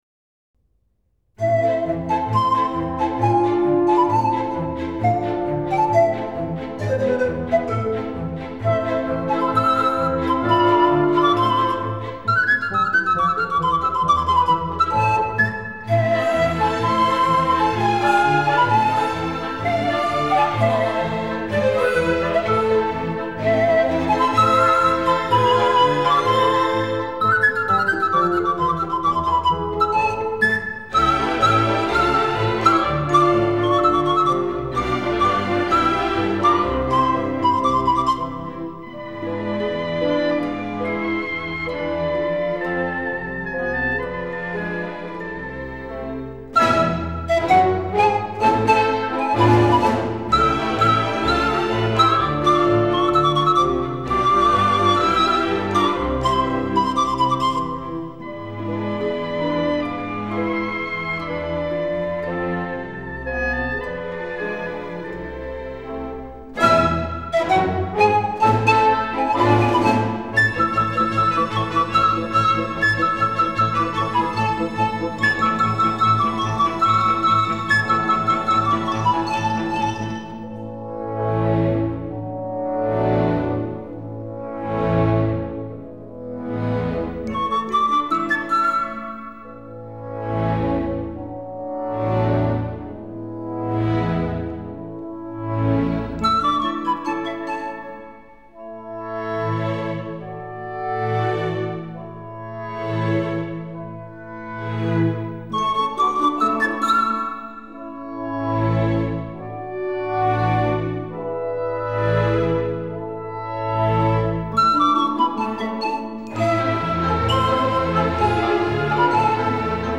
ну мелодичней флейты ничего не придумать